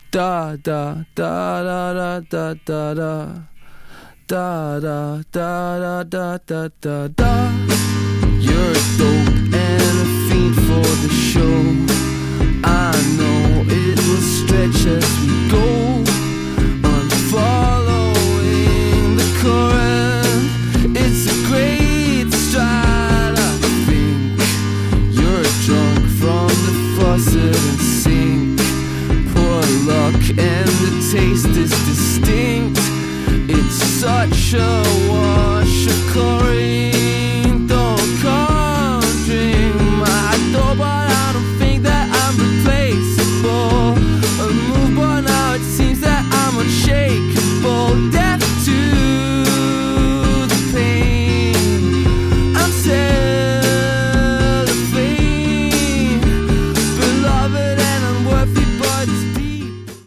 New Release Indie Rock New Wave / Rock